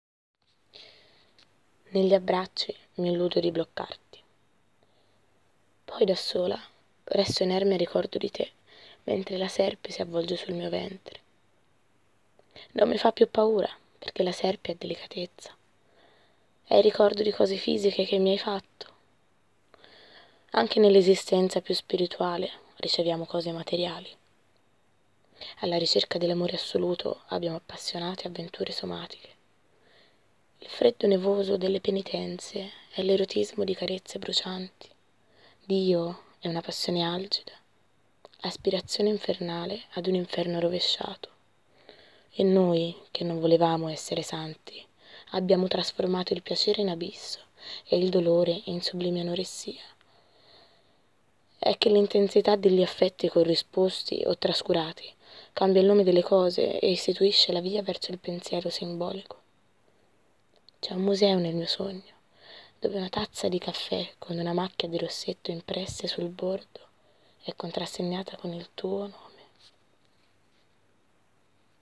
dettato numero due